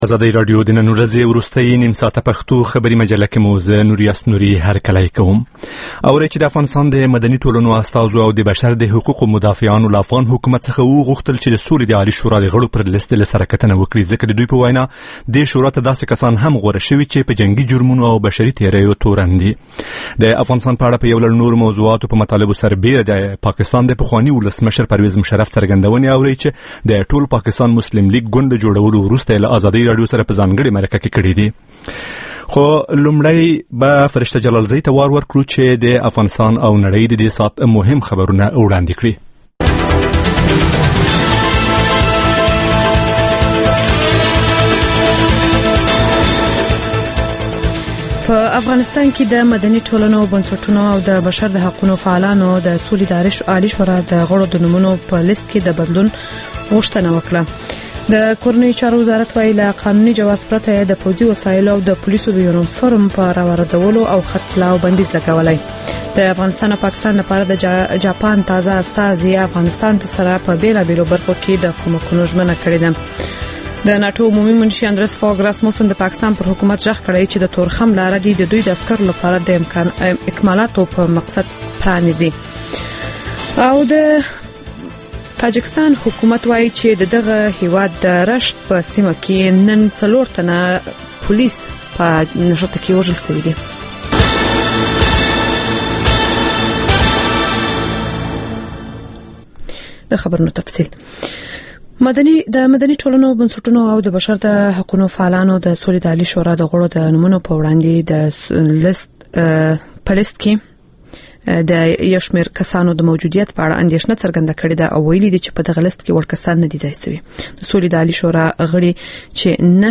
ماښامنۍ خبري مجله